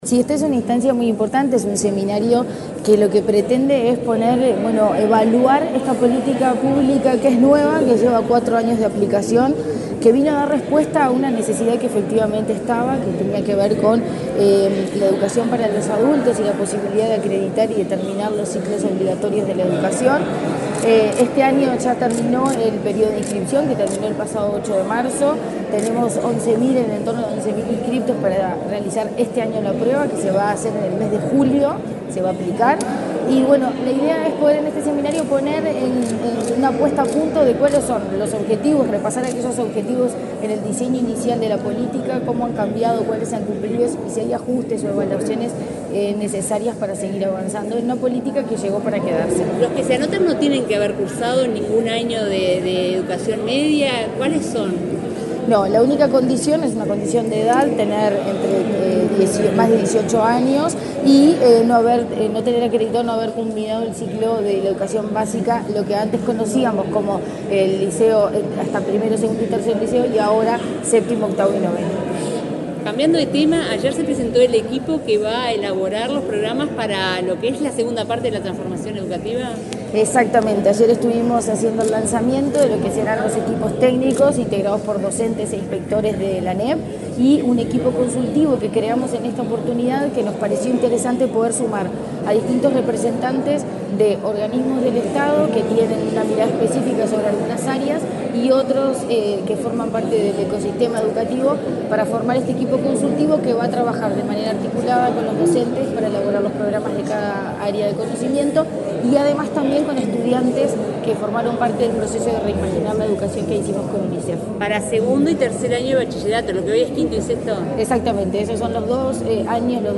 Declaraciones de la presidenta de ANEP, Virginia Cáceres
Declaraciones de la presidenta de ANEP, Virginia Cáceres 25/04/2024 Compartir Facebook Twitter Copiar enlace WhatsApp LinkedIn Este jueves 25 en Montevideo, la presidenta de la Administración Nacional de Educación Pública (ANEP), Virginia Cáceres, participó en la apertura de un seminario enfocado en la experiencia de la Prueba Nacional de Acreditación de la Educación Media Básica (AcreditaCB). Luego dialogó con la prensa.